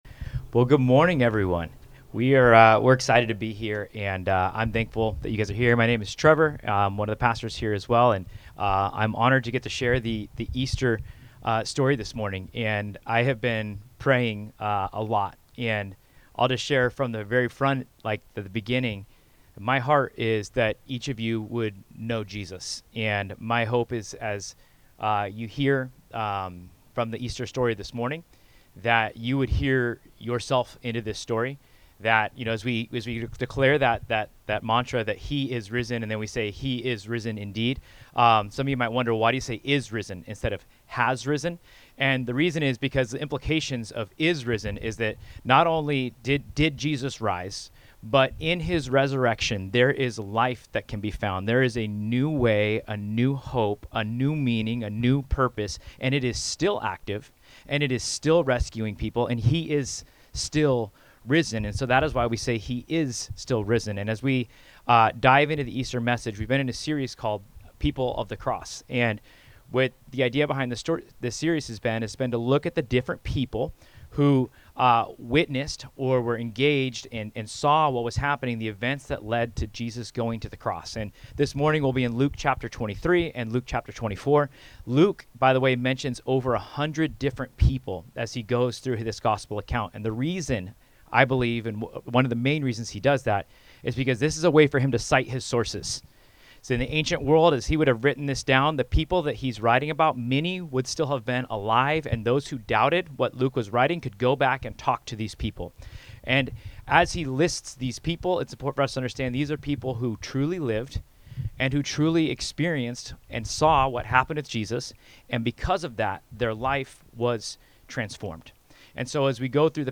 Various Service Type: Sunday Come listen in!